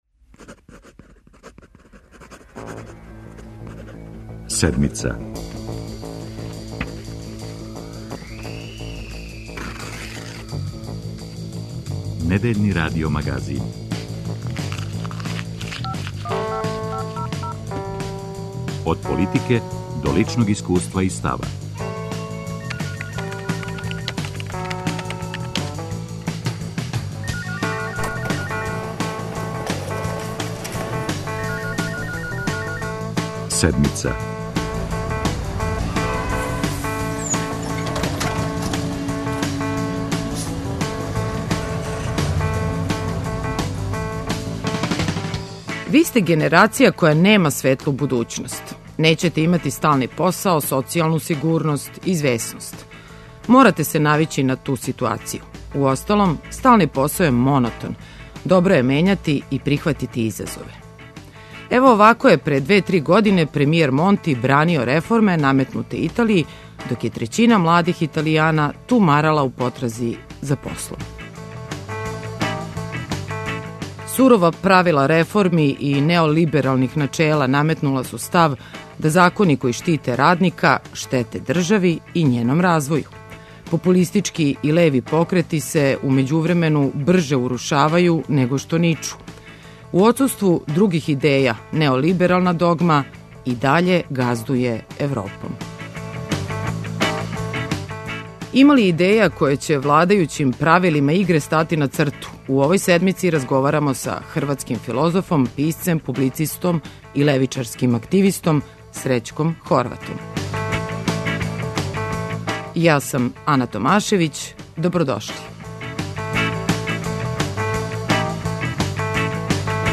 За Седмицу говори левичар, филозоф и публициста Срећко Хорват.